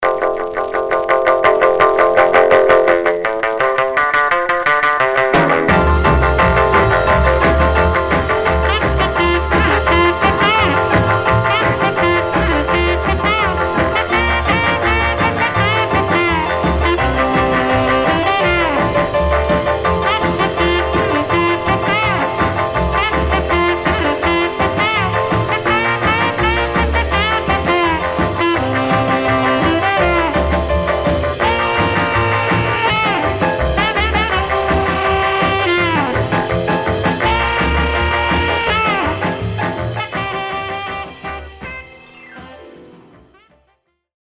Surface noise/wear Stereo/mono Mono
R & R Instrumental